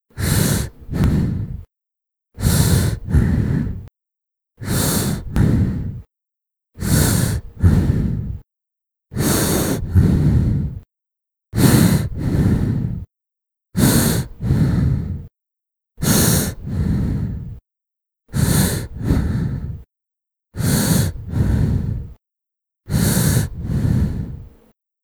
gas_mask_middle_breath2.wav